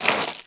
SPADE_Empty_01_mono.wav